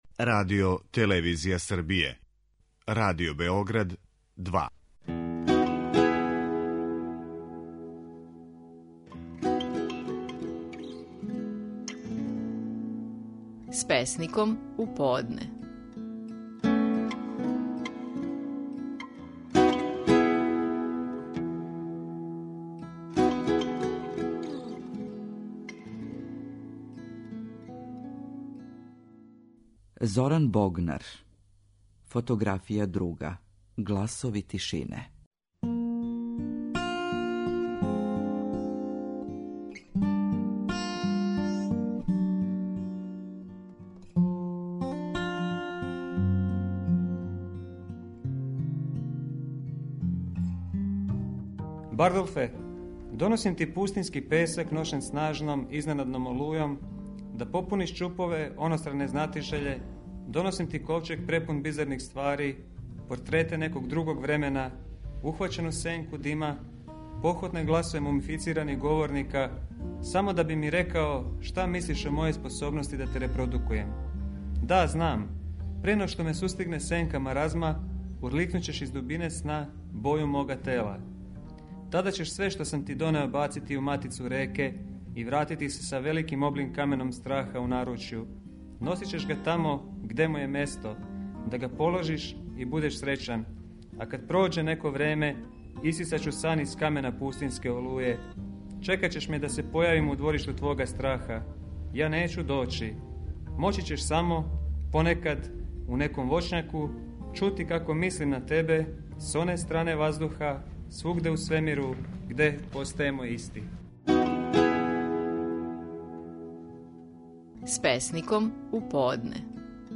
Наши најпознатији песници говоре своје стихове.